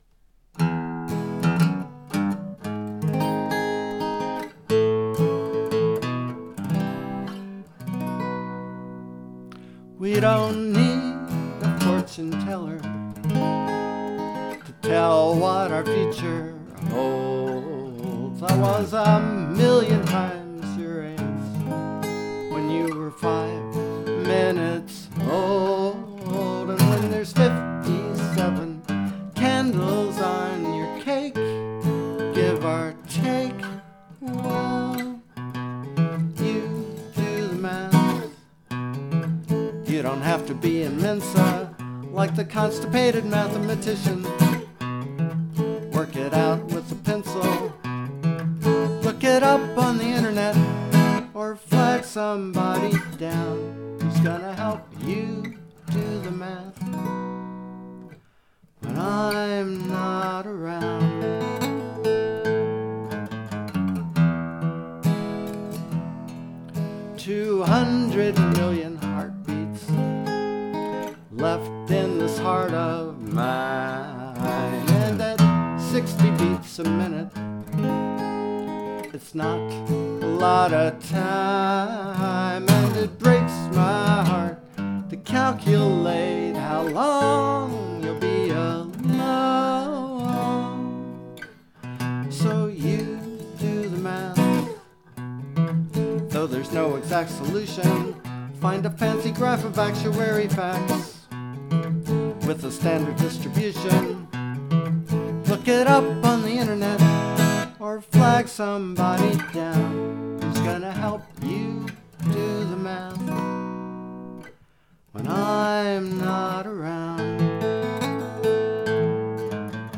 A love song you can solve using algebra